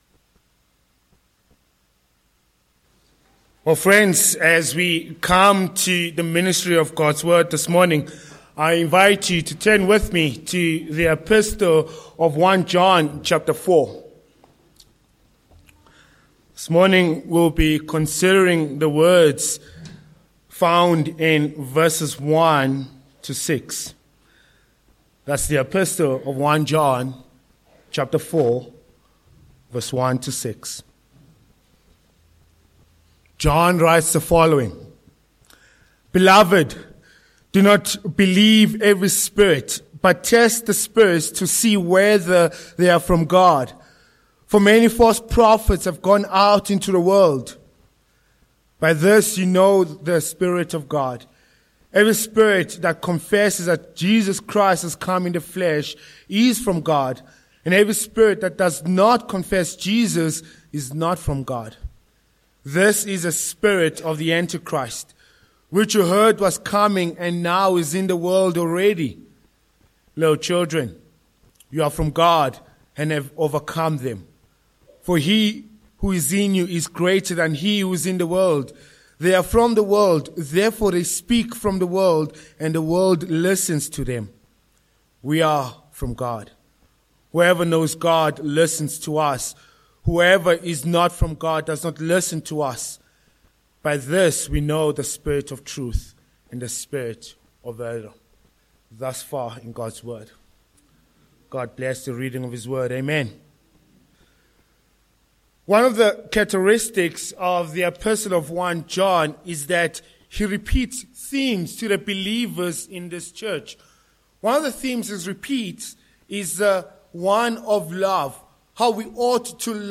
Sermon points: 1. The Threat of false teachers v1,5
Service Type: Morning